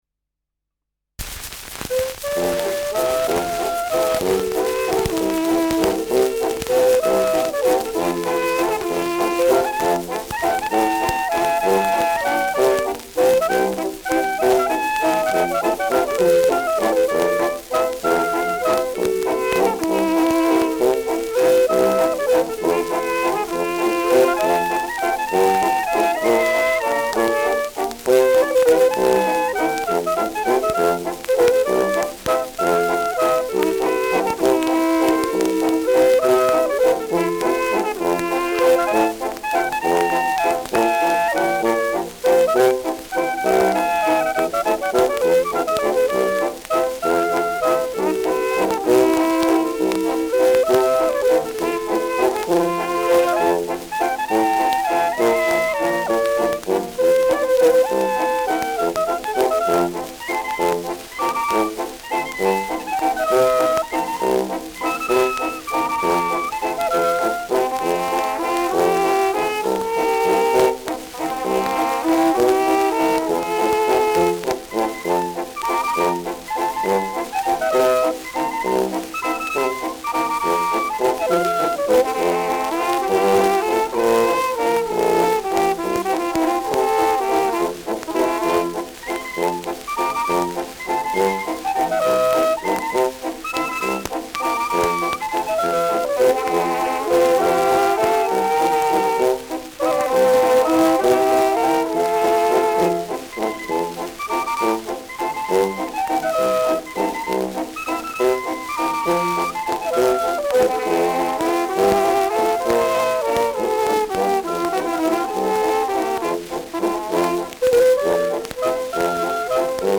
Walzer
Schellackplatte
Tonrille: Abrieb : graue Rillen : Kratzer 3 / 6 Uhr
präsentes Rauschen
Mit Juchzern.